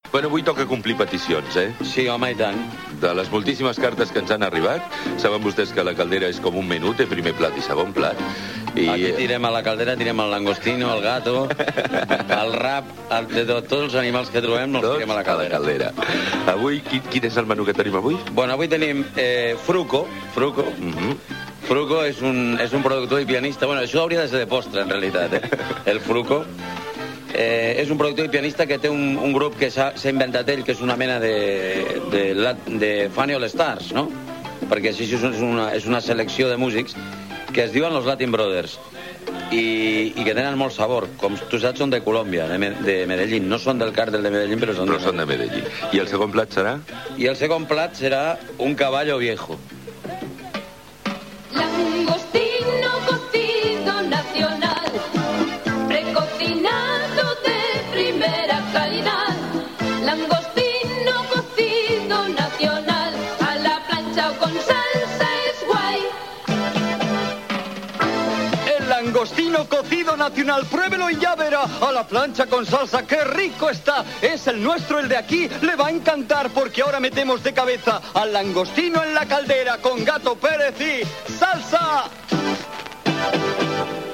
Comentaris musicals del cantant Gato Pérez (Xavier Patricio Pérez) sobre el cantant colombià Fruko (Latin Brothers), publicitat
Entreteniment